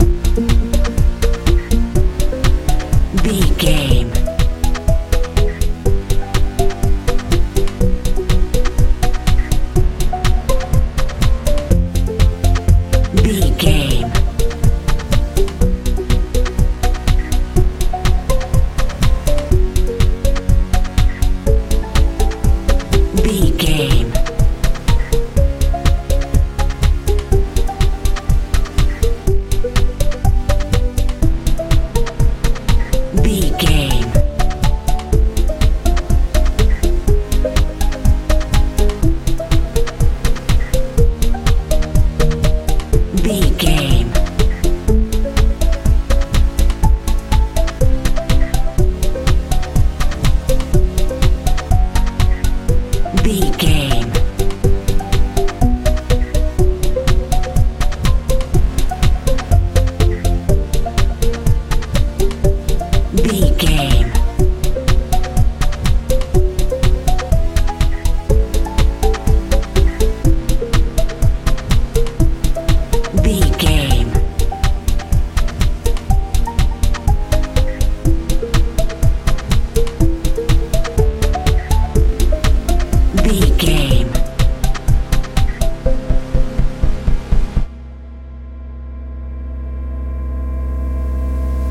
dance feel
Ionian/Major
magical
mystical
synthesiser
bass guitar
drums
80s
90s